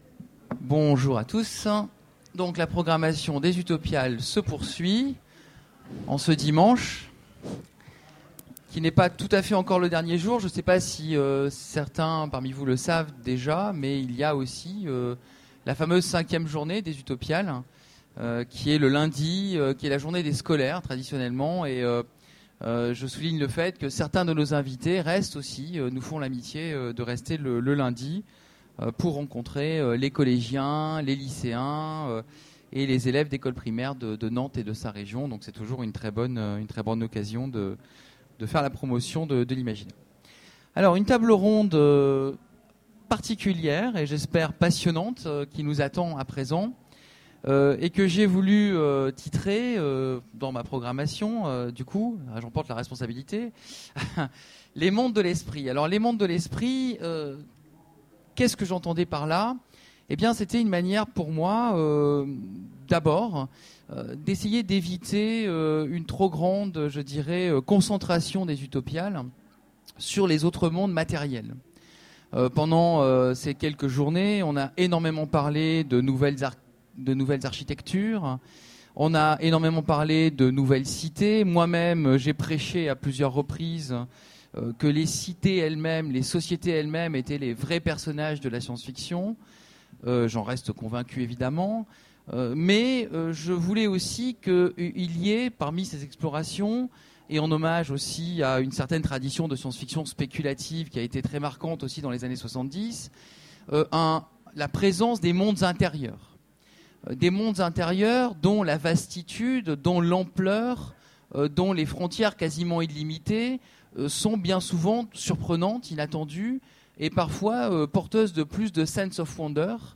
Utopiales 13 : Conférence Les mondes de l'esprit